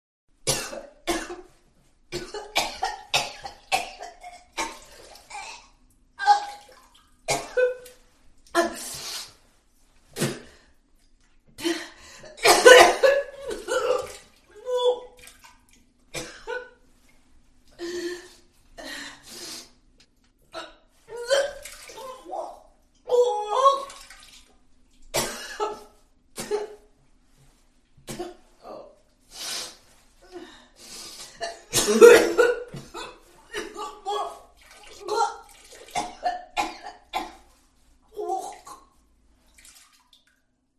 Tiếng Ho và Nôn, ọe tiếng Phụ Nữ, con Gái
Thể loại: Tiếng con người
Description: Vomiting In Toilet, Vomit Sound Effects, Woman Vomiting, Nôn trong nhà vệ sinh, Hiệu ứng âm thanh nôn, Phụ nữ nôn, đau ốm, có thai...
tieng-ho-va-non-oe-tieng-phu-nu-con-gai-www_tiengdong_com.mp3